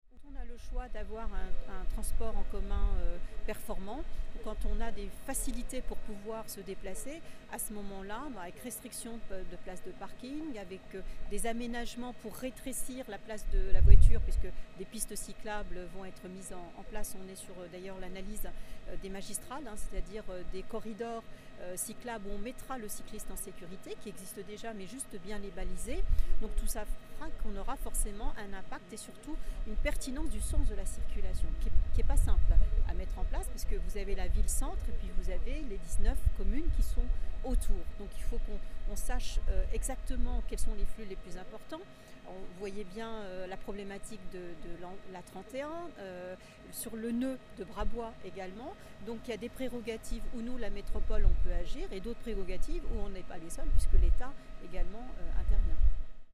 Interrogée sur la question, madame Malika DATI, vice-présidente du grand Nancy délégée aux transports, nous explique :